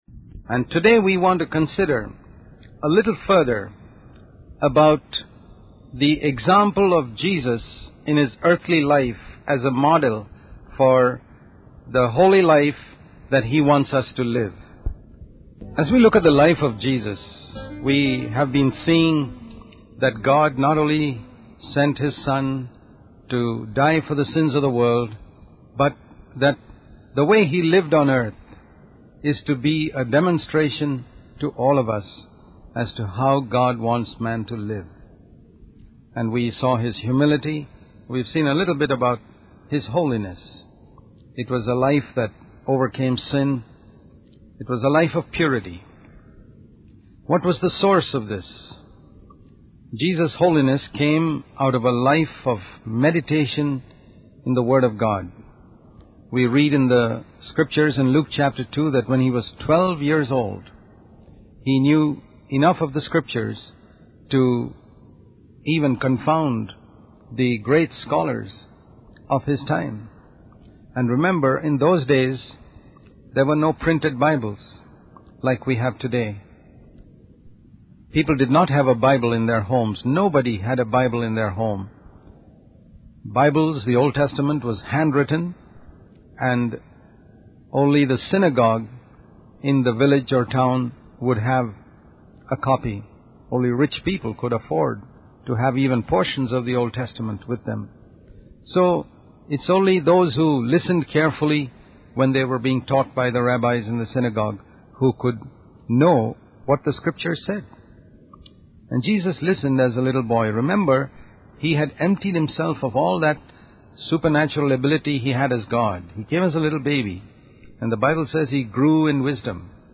In this sermon, the speaker emphasizes the importance of following the example of Jesus in living a holy life. Jesus' humility and holiness are highlighted as characteristics that we should strive to emulate.